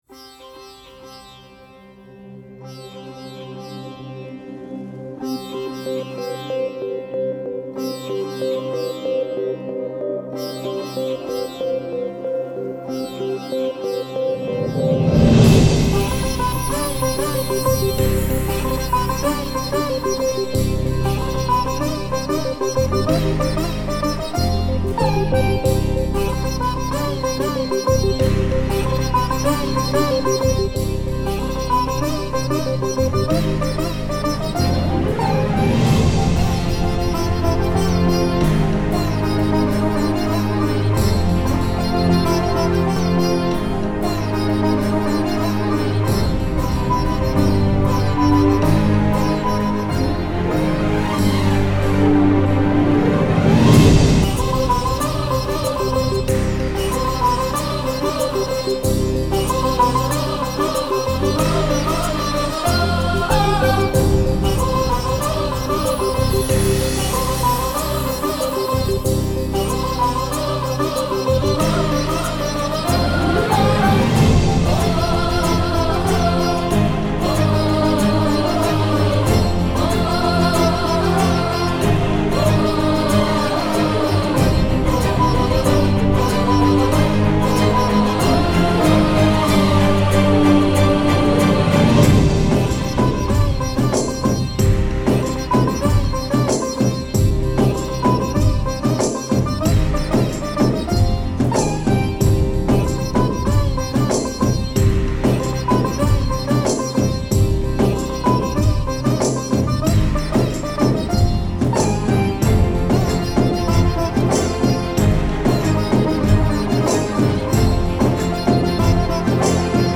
without dialogues